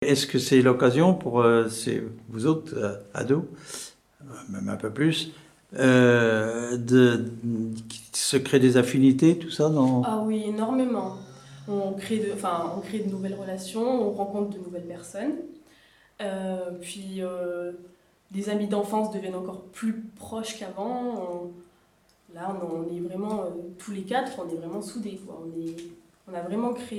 Enquête Enquête ethnologique sur les fêtes des bouviers et des laboureurs avec l'aide de Témonia
Catégorie Témoignage